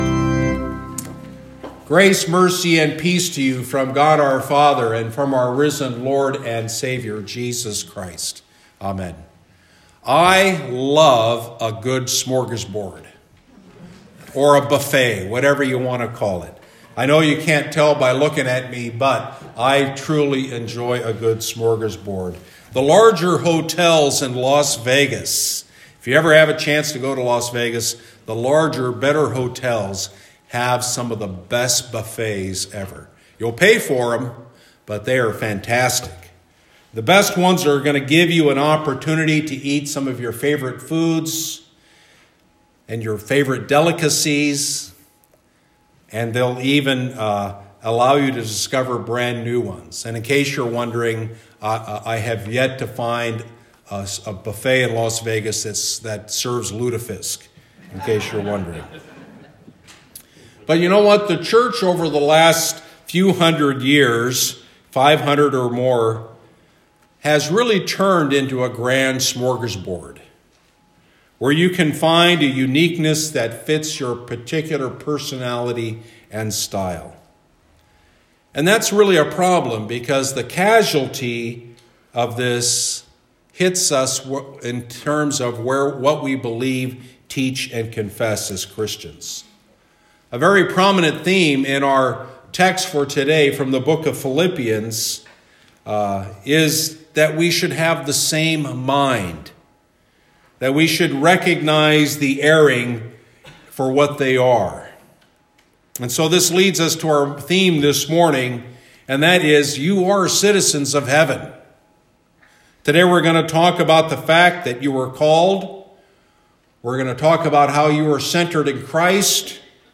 Second Sunday in Lent -- Sermon Readings Jeremiah 26:8-15 Psalm 4 Philippians 3:17-4:1 Luke 13:31-35